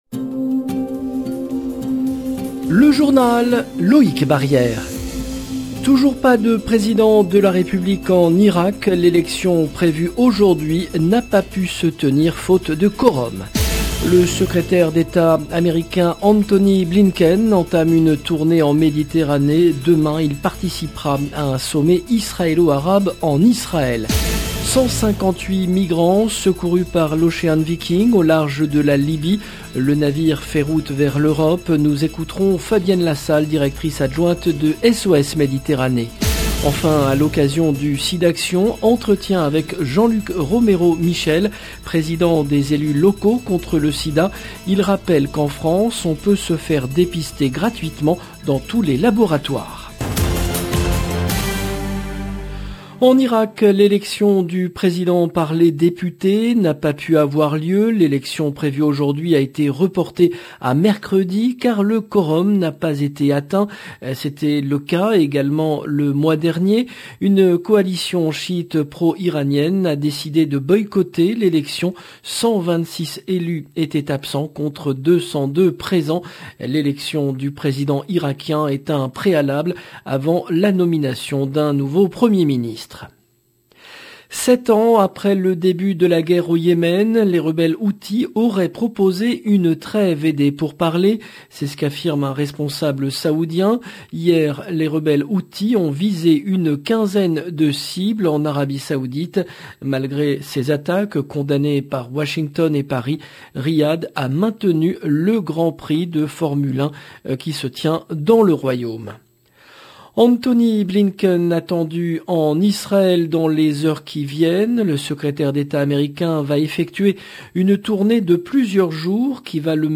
LB JOURNAL EN LANGUE FRANÇAISE
A l’occasion du Sidaction, entretien avec Jean-Luc Romero-Michel, président des Elus locaux contre le sida.